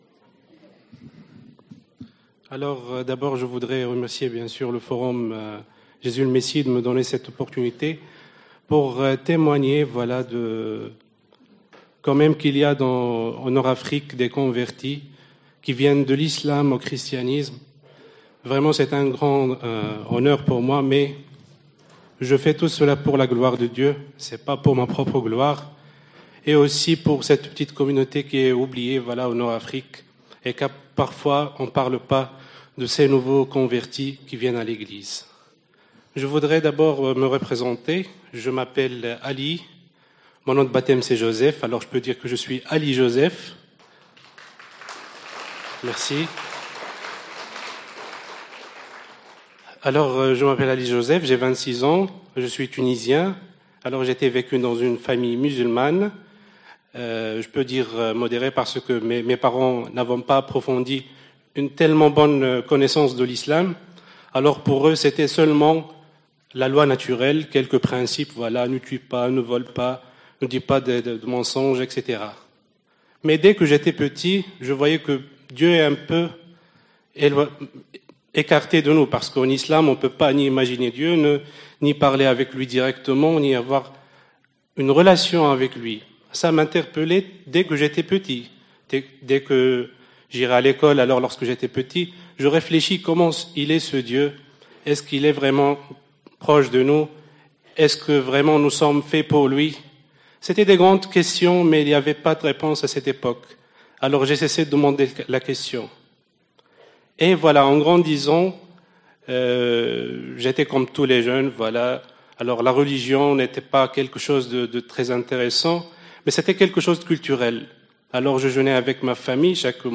Forum des 2 & 3 février 2019 - PARIS